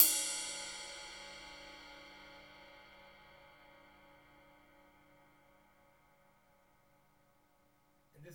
Index of /90_sSampleCDs/Roland L-CDX-01/KIT_Drum Kits 4/KIT_Hard Core
CYM ET RID0F.wav